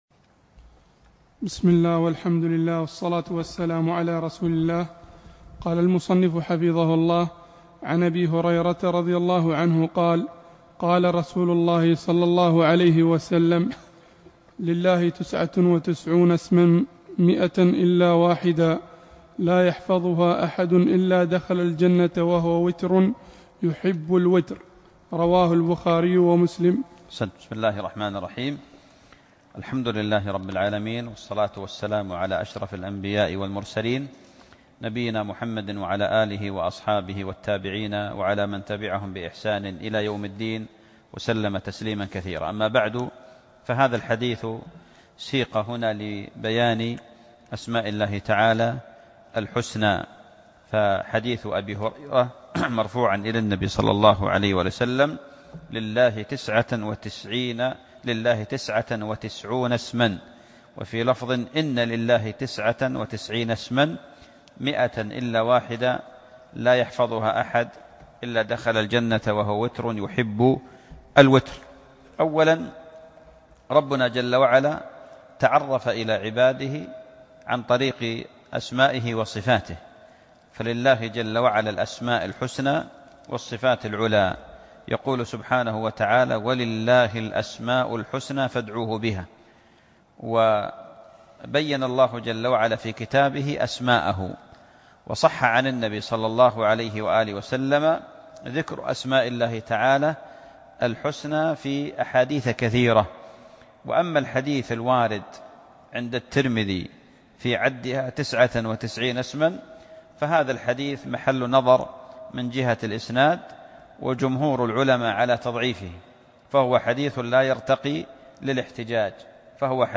الدرس الثامن عشر